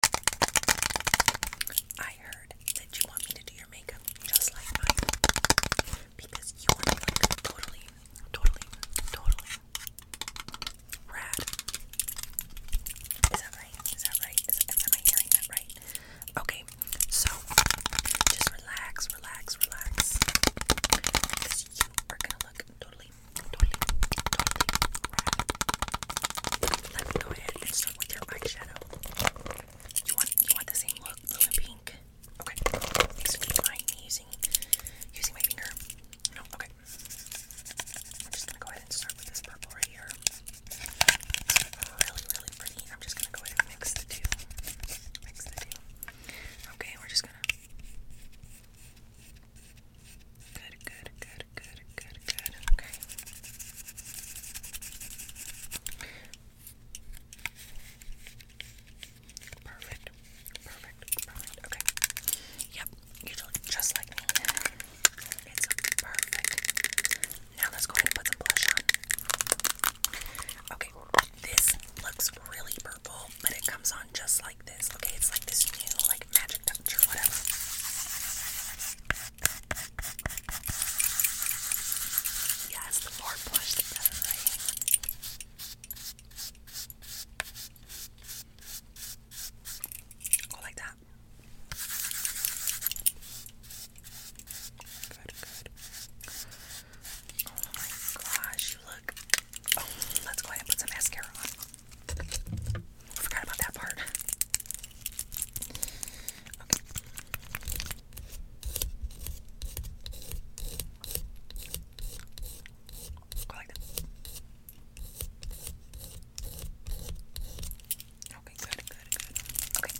80s MAKEOVER! 80s ASMR mini-series